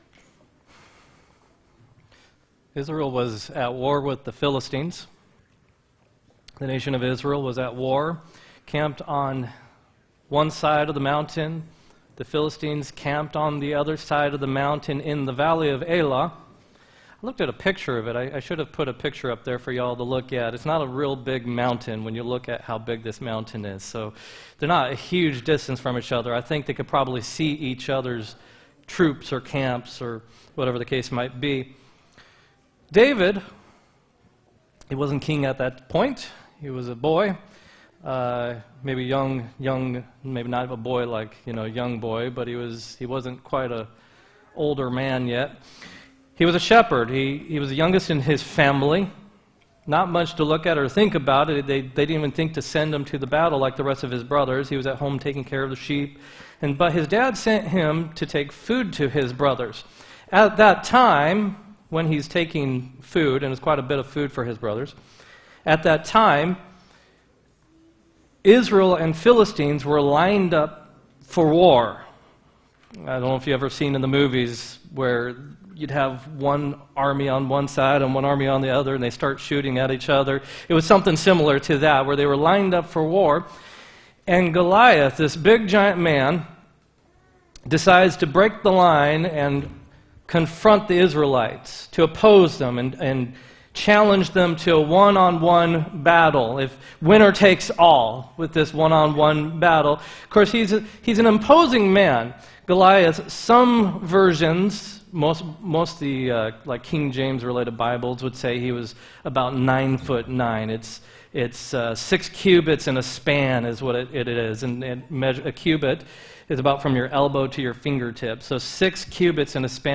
2-29-20 sermon